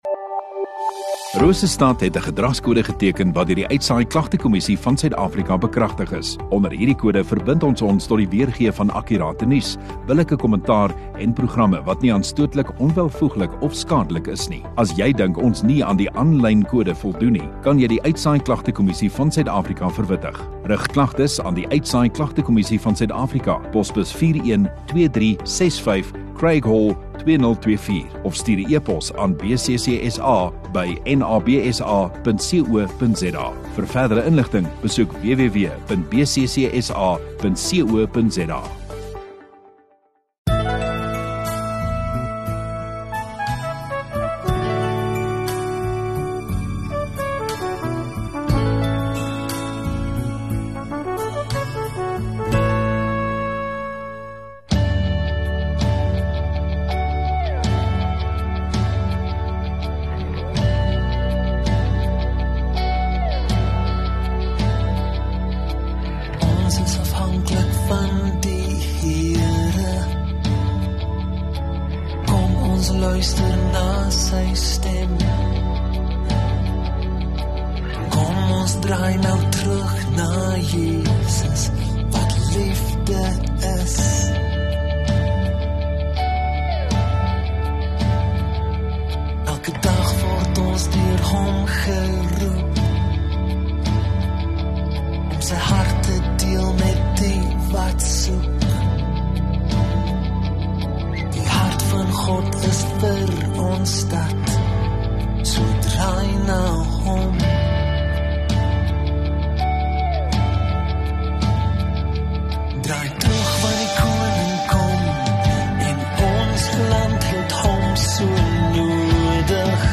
7 Apr Sondagoggend Erediens